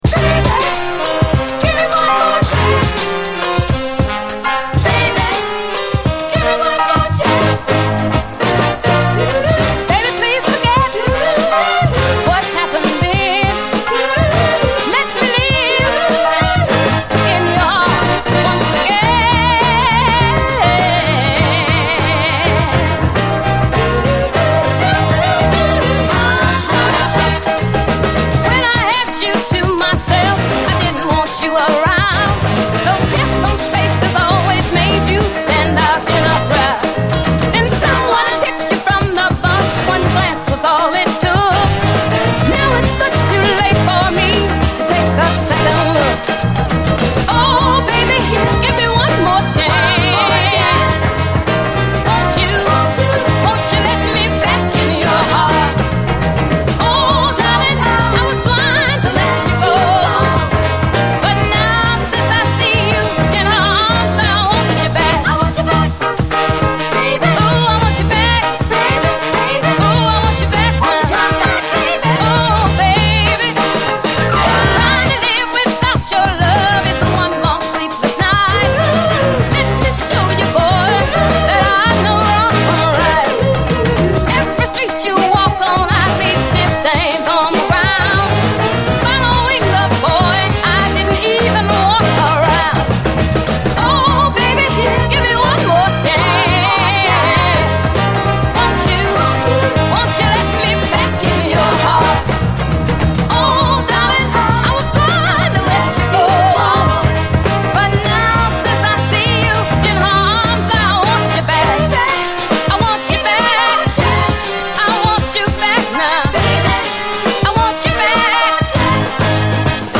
ビシバシと切れ味鋭い濃密レアグルーヴ・サウンドが充満してます！
ノーザン〜モダン・ソウル・トラック